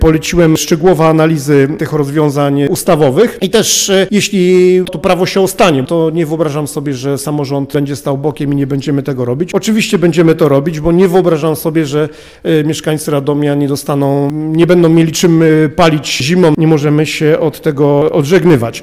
Tak wynika z proponowanych w ustawie terminów, powiedział na sesji Rady Miejskiej prezydent Radomia Radosław Witkowski:
Prezydent Witkowski dodał, że wprawdzie nie podoba mu się pomysł dystrybucji węgla, jednak nie zamierza stać plecami do potrzeb mieszkańców: